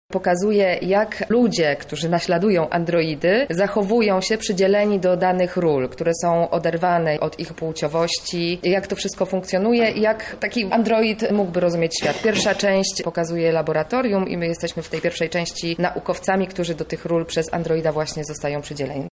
HMLT aktorka